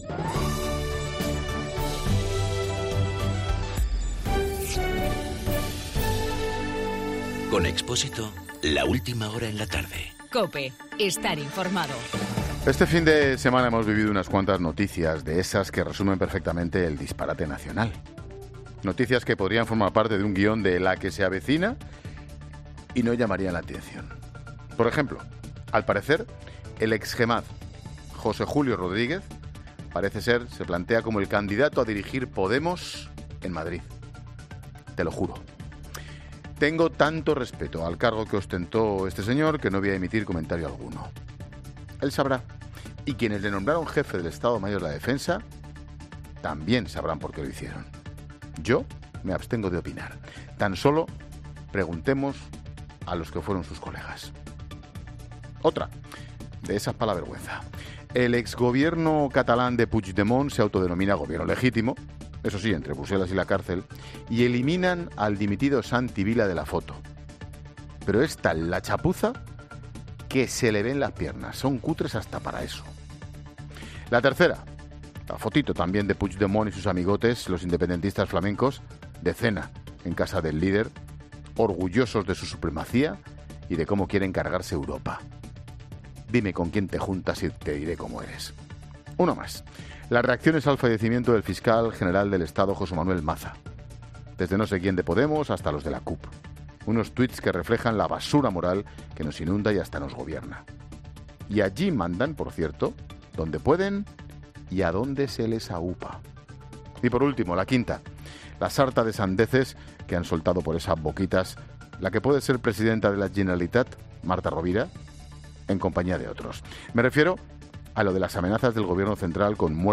AUDIO: El comentario de Ángel Expósito con cinco noticias que podrían resumir la situación actual, "el disparate nacional".